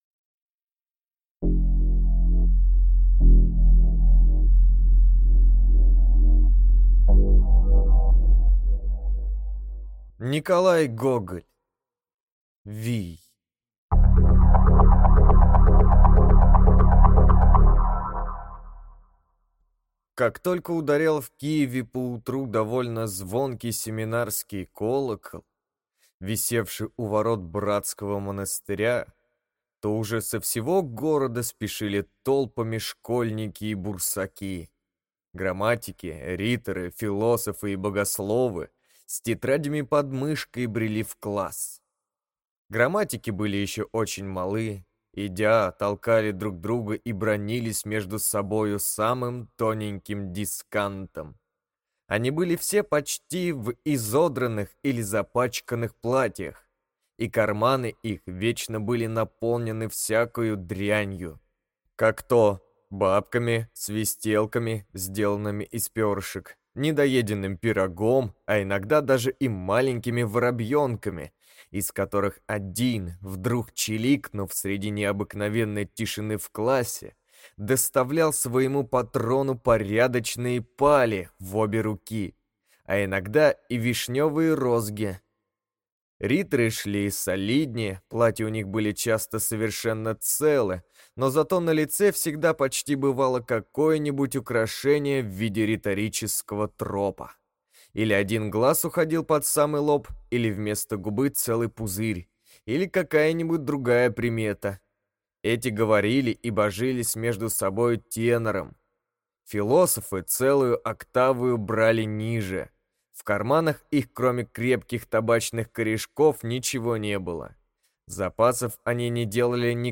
Аудиокнига Вий | Библиотека аудиокниг